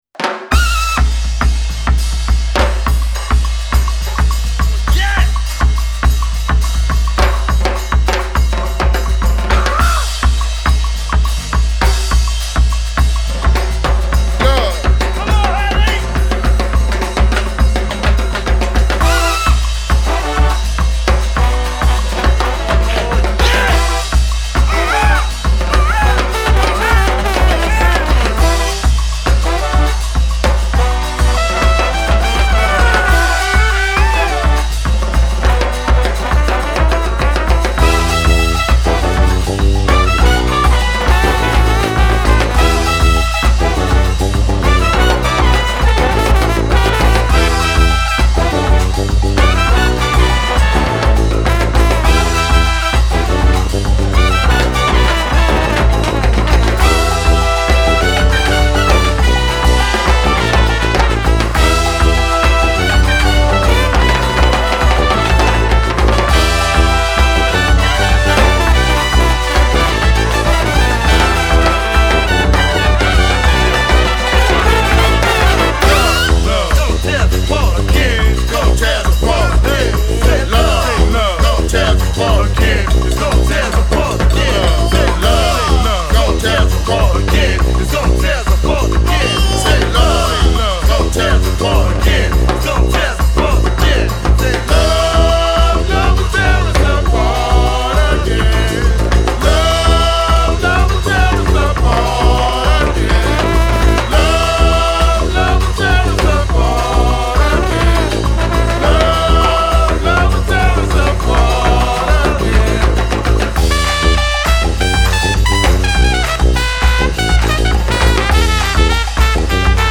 echoing the piece’s twirling New Wave fanfare reminiscence.